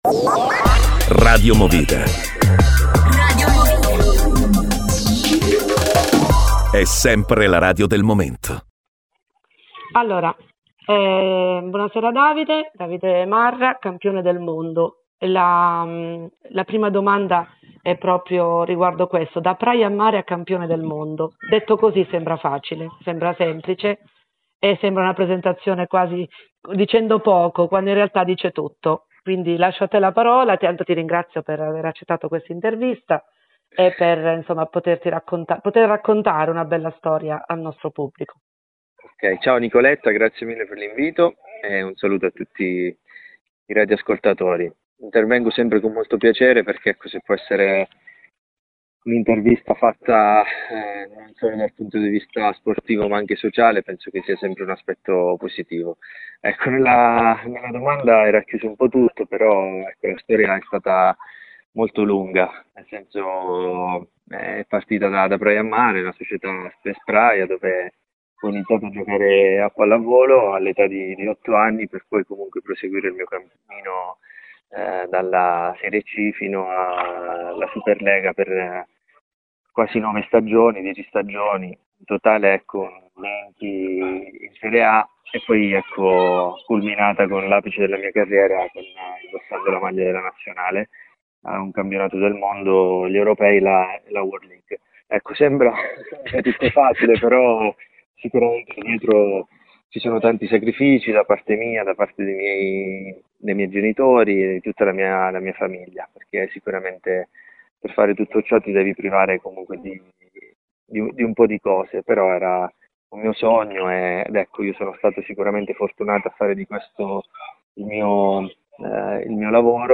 INTERVISTA.mp3